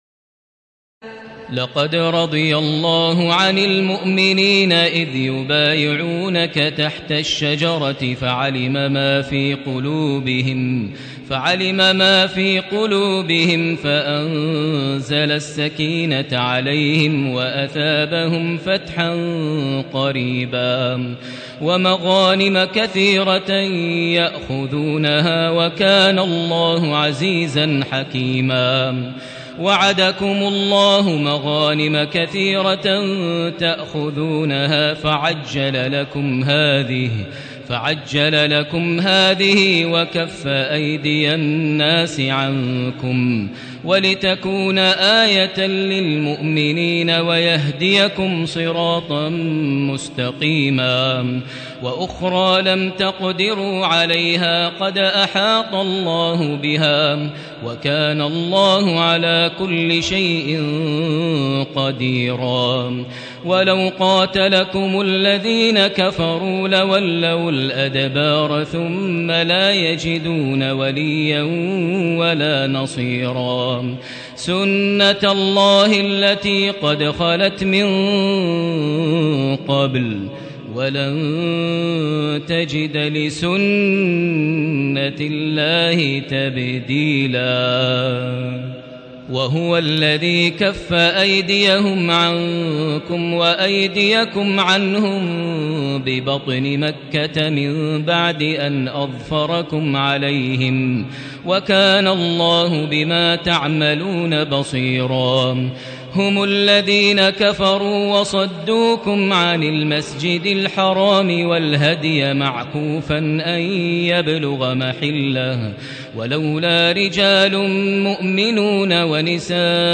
تراويح ليلة 25 رمضان 1437هـ من سور الفتح (18-29) الحجرات و ق و الذاريات (1-37) Taraweeh 25 st night Ramadan 1437H from Surah Al-Fath and Al-Hujuraat and Qaaf and Adh-Dhaariyat > تراويح الحرم المكي عام 1437 🕋 > التراويح - تلاوات الحرمين